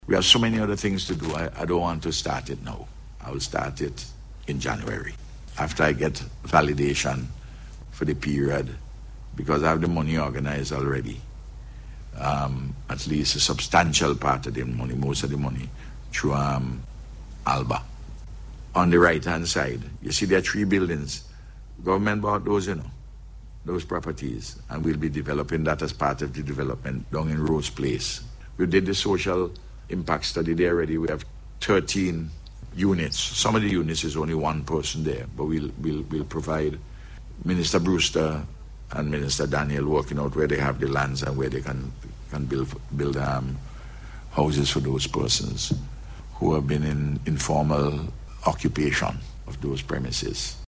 Speaking at a media conference hosted by the St. Vincent and the Grenadines Port Authority yesterday, Prime Minister Dr. Ralph Gonsalves said the transformation will begin early next year.